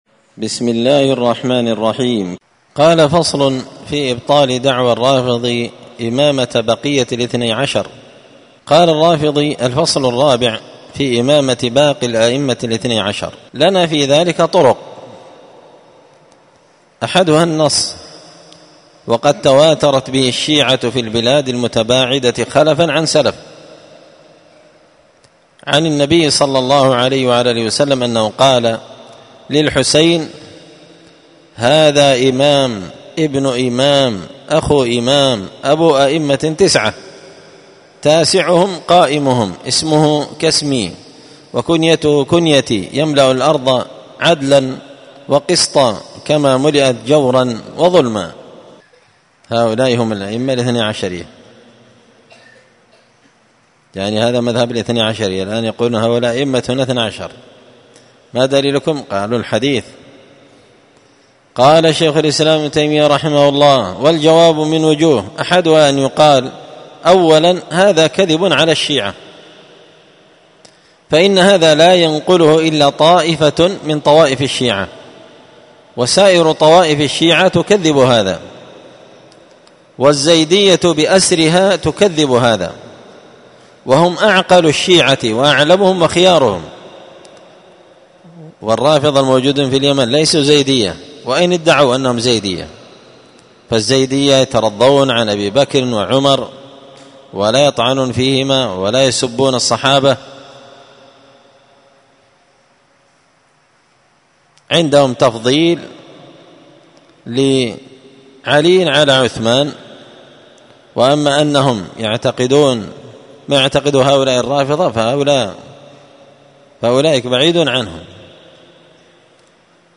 الدرس الثاني والعشرون بعد المائتين (222) فصل في إبطال دعوى الرافضي إمامة بقية الإثني عشر